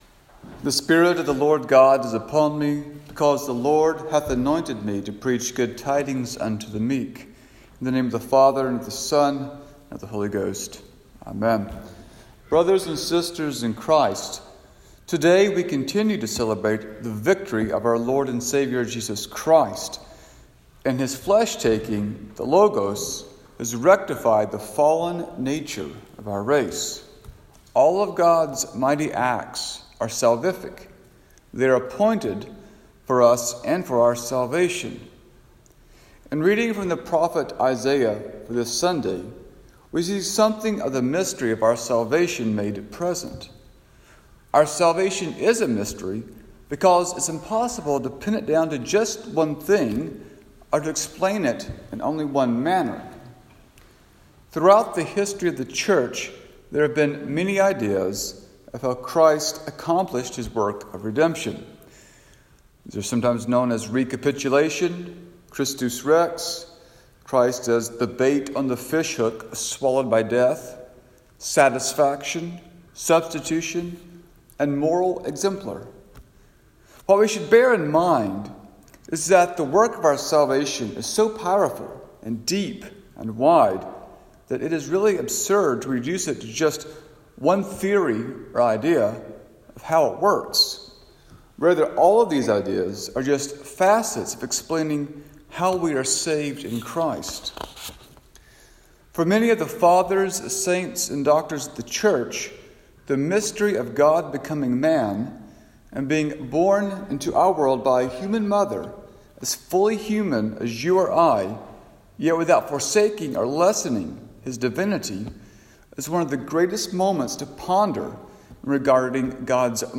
Sermon Notes for Christmas 2 - 2022